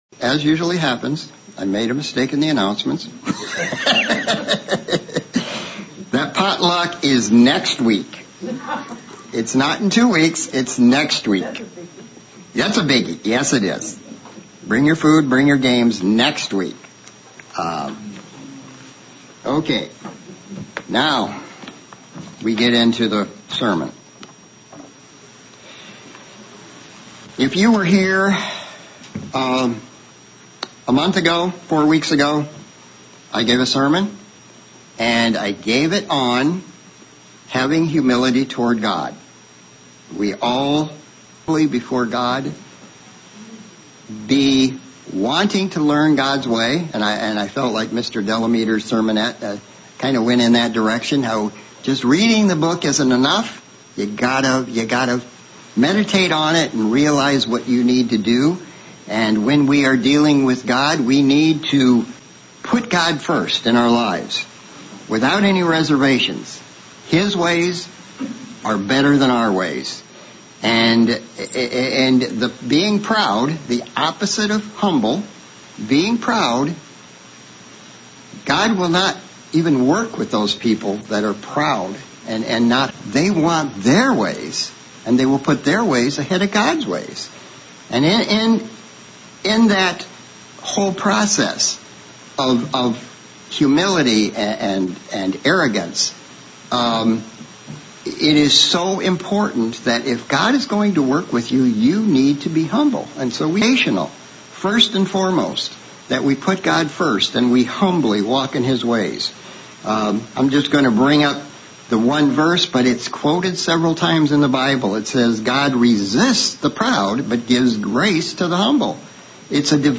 Sermon looking at something God values highly - Humility. Looking thru the Bible to see humility in action and 4 points that contrast the traits of humility with that of the proud.